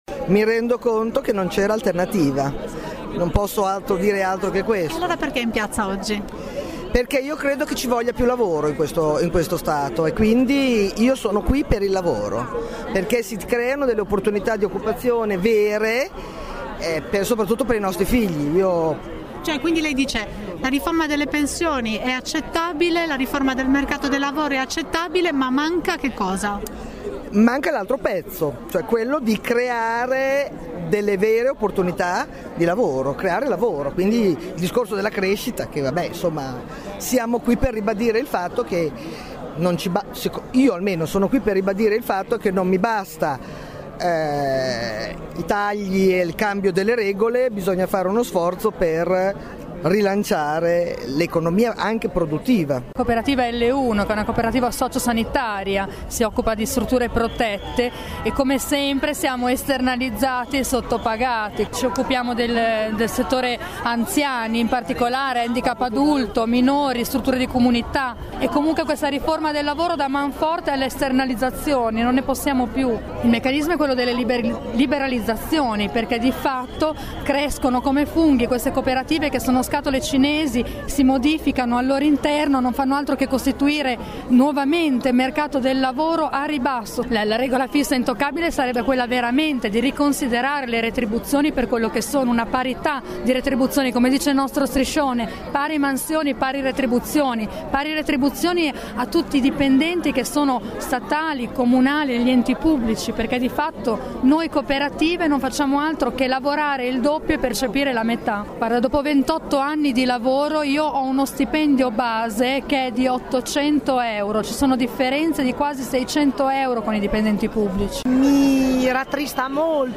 voci-corteo-cgil-sito.mp3